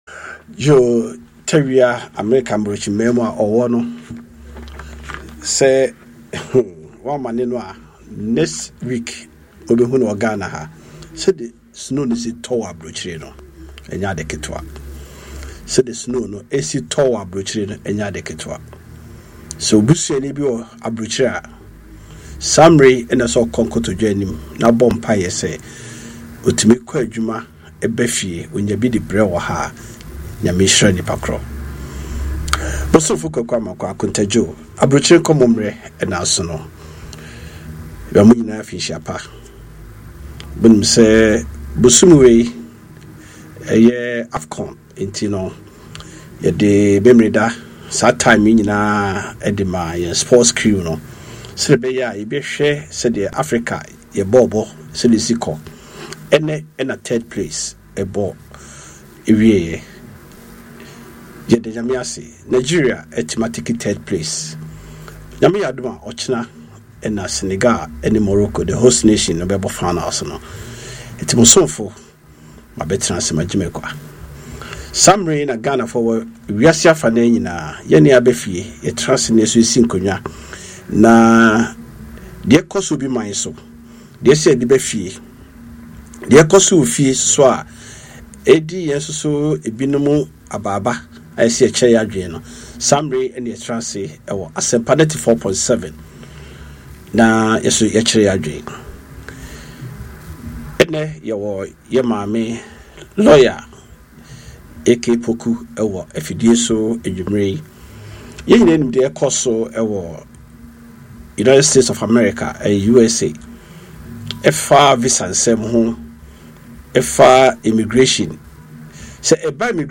Chit-chat with Ghanaians living abroad, discussing life overseas with all its enjoyments and struggles.